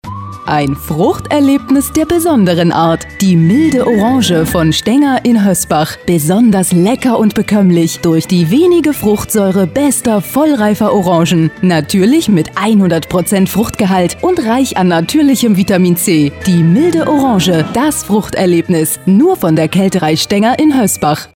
Sprecherin deutsch. Stimmalter Mitte 30. Sprecherin fĂŒr Werbung / OFF / Industriefilm / HĂ¶rspiel / Podcast
Sprechprobe: eLearning (Muttersprache):
female german voice over. Voice over artist for commercials, tv, radio, synchron, audio-books, documentaries, e-learning, podcast